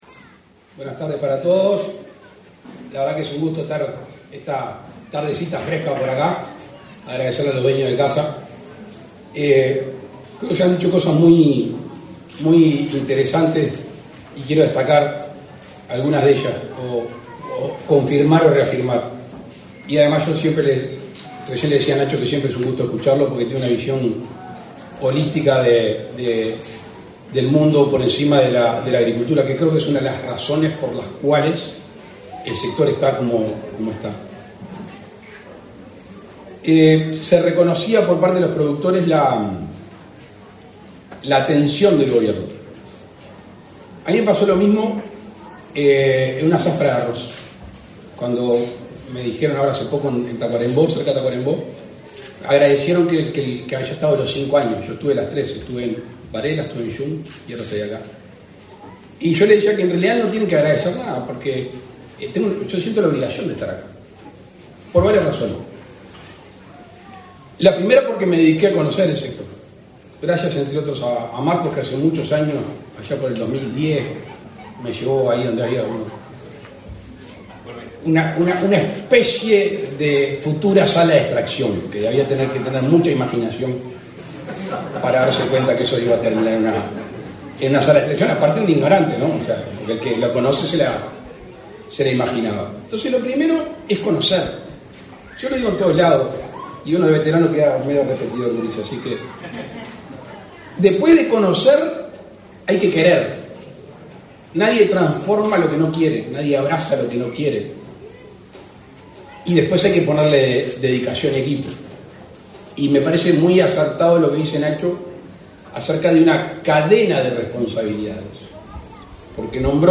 Palabras del presidente de la República, Luis Lacalle Pou
Palabras del presidente de la República, Luis Lacalle Pou 06/11/2024 Compartir Facebook X Copiar enlace WhatsApp LinkedIn Con la presencia del presidente de la República, Luis Lacalle Pou, se realizó, este 6 de noviembre, el lanzamiento de la zafra de la miel 2024, en la localidad de Sarandí Grande, en el departamento de Florida.